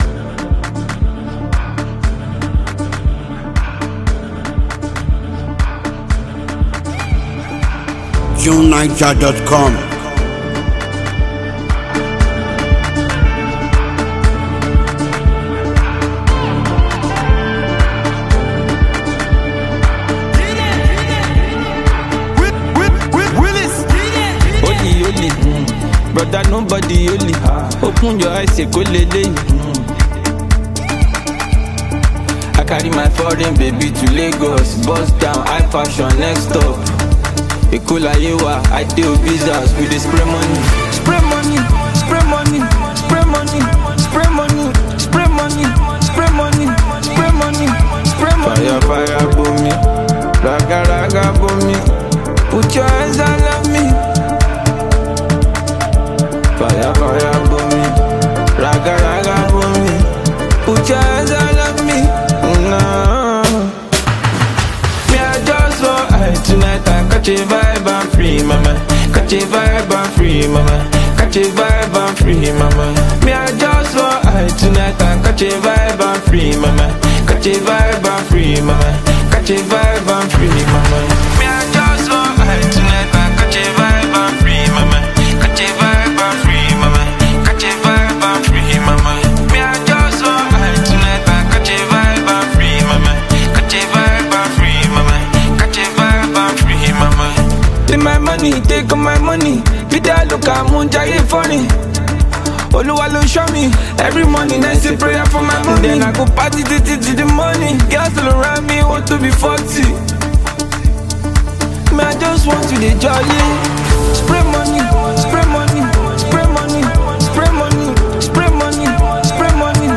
good music with a laid-back vibe